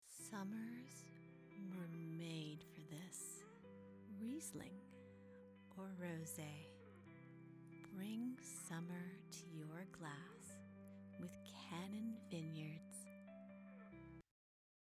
Female
Television Spots
Internet/Tv Spot For Wine